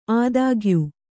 ♪ ādāgyū